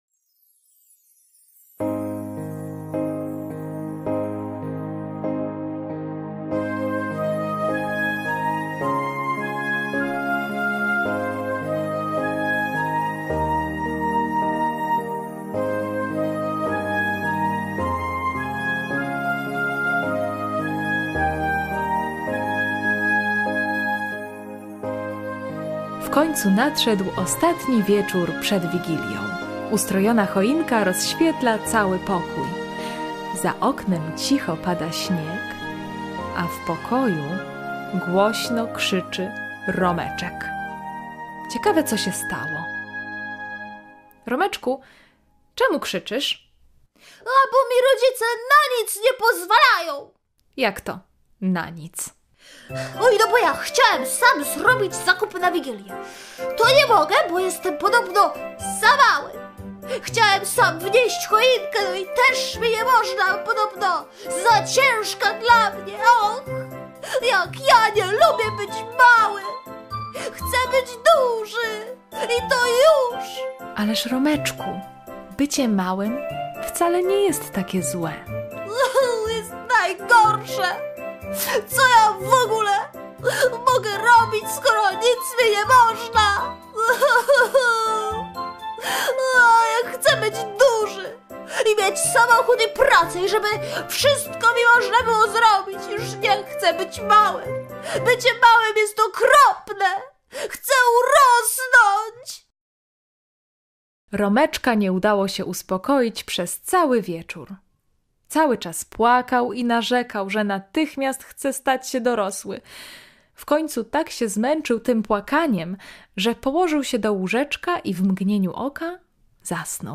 Jak Romeczek stał się dorosły! [ŚWIĄTECZNA AUDYCJA DLA DZIECI]